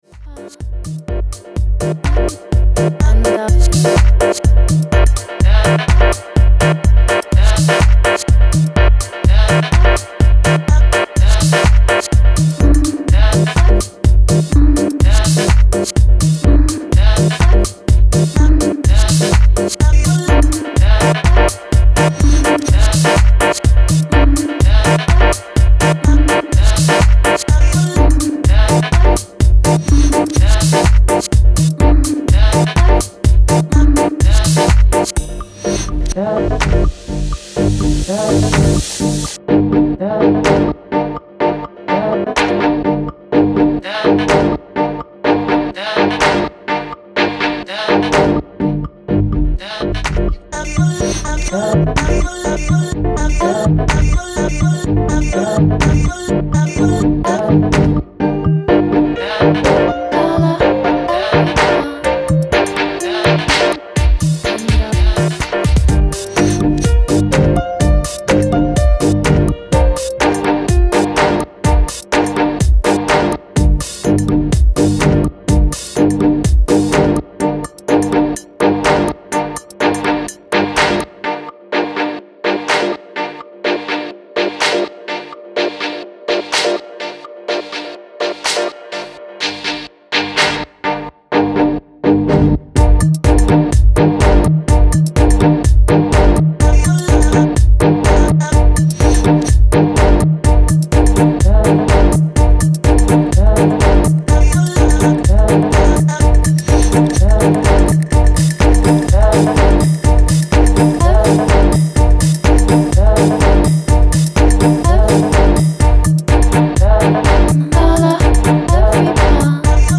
Style: House / Tech House